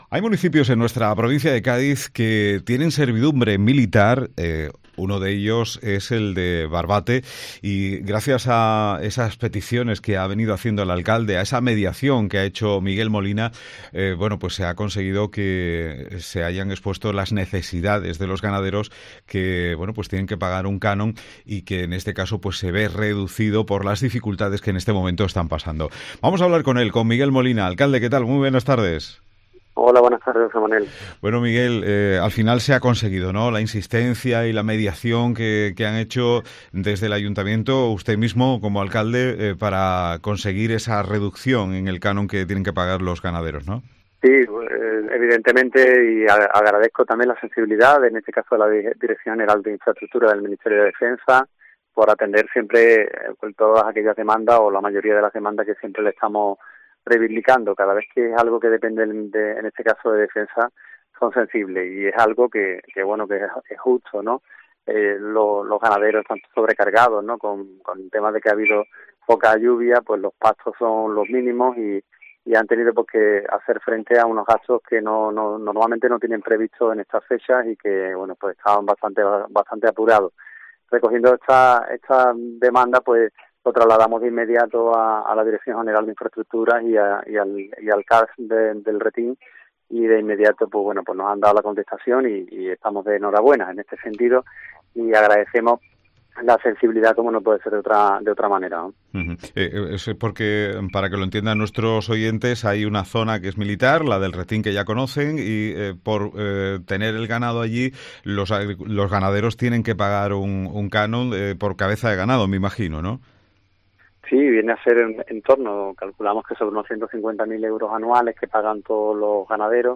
Miguel Molina, Alcalde de Barbate - Servidumbre militar del Retín y reducción del canon a los ganaderos